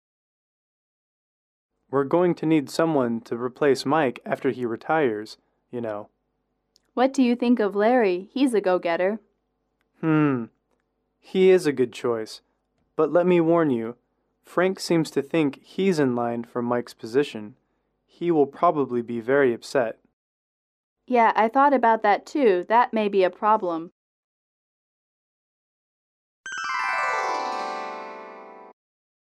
英语主题情景短对话17-2：接班人(MP3)